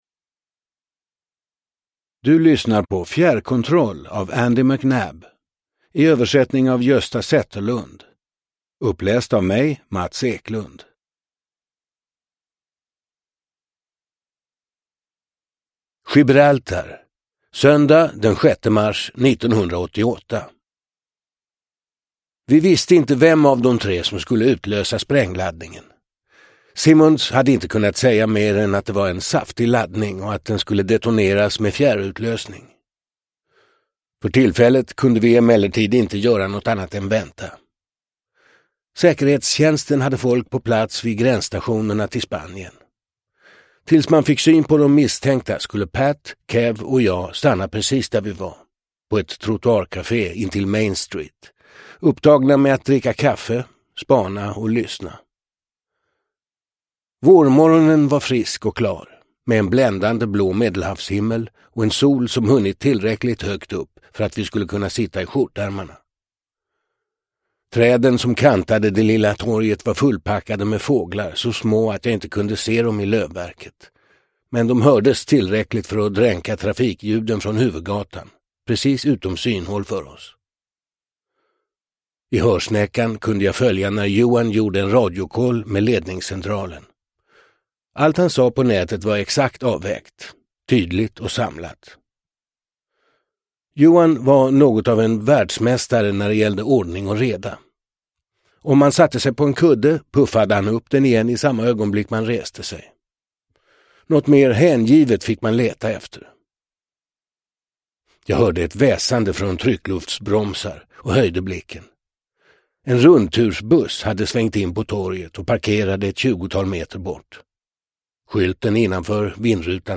Fjärrkontroll – Ljudbok – Laddas ner